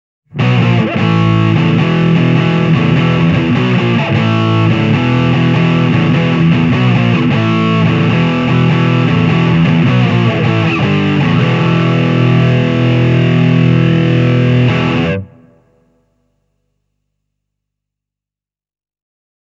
Ja tässä käytetään kahdella humbuckerilla varustettua Hameria (Drive täysillä):